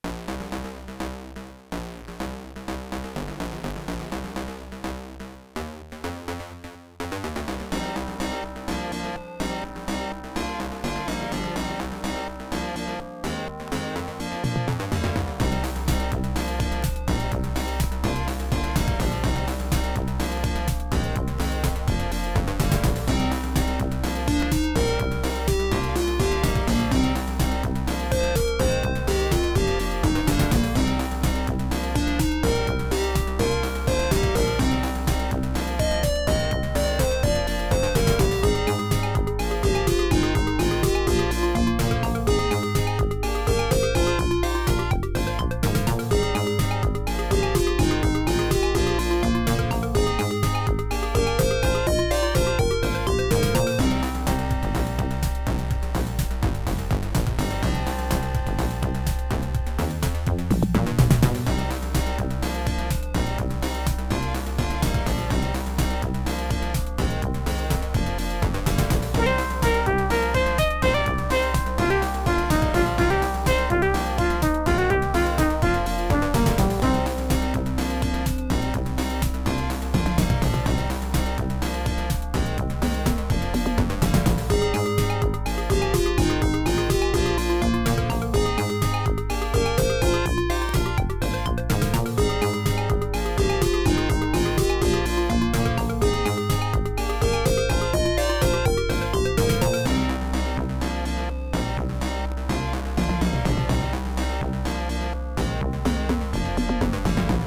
Music: midi
Creative SoundBlaster 16 ct2740(adlib mode)
* Some records contain clicks.